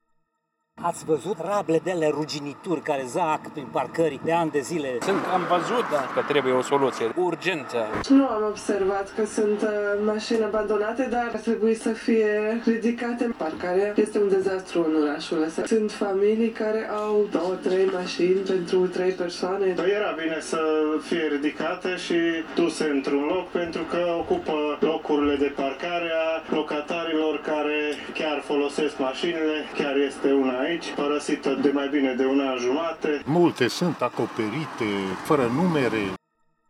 Târgumureșenii așteaptă de ani de zile ca aceste mașini abandonate să fie ridicate, pentru că blochează parcările dintr-un oraș deja sufocat de autoturisme: